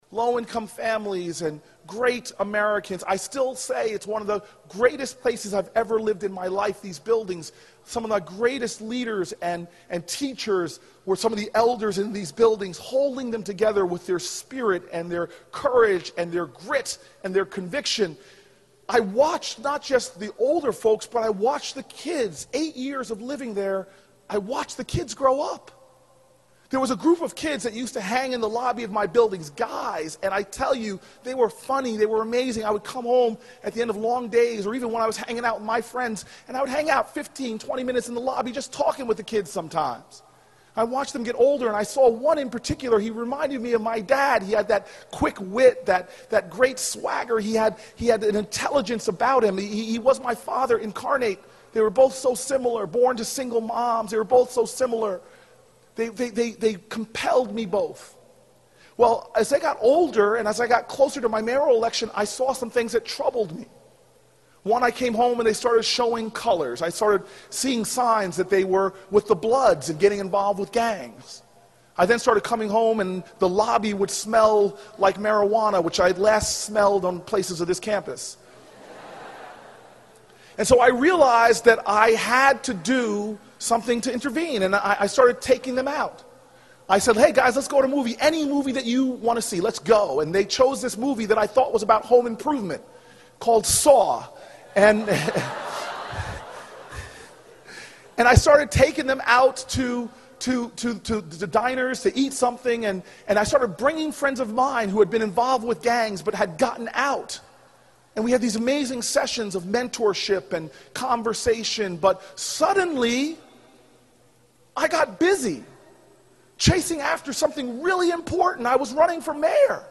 公众人物毕业演讲第446期:科里布克2013年耶鲁大学(14) 听力文件下载—在线英语听力室